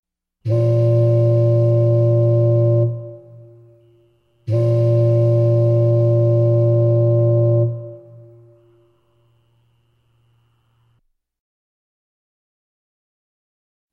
Гудок парохода короткий и длинный звук